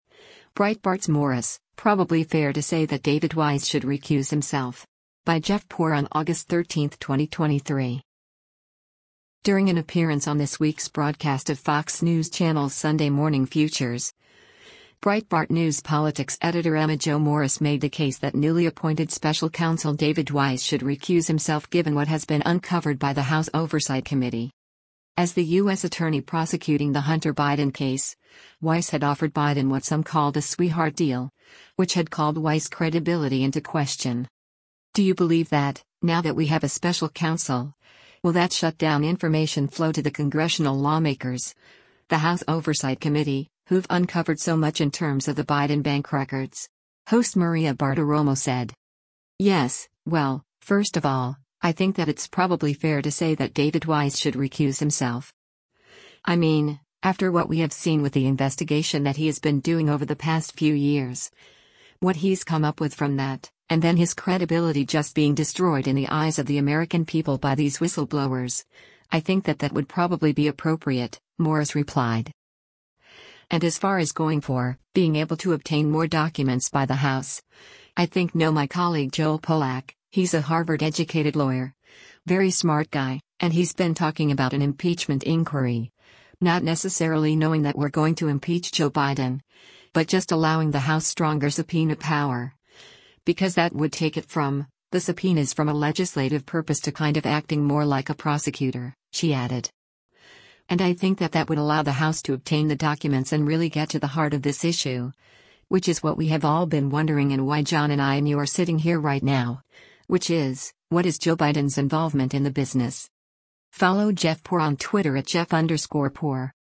“Do you believe that, now that we have a special counsel, will that shut down information flow to the congressional lawmakers, the House Oversight Committee, who’ve uncovered so much in terms of the Biden bank records?” host Maria Bartiromo said.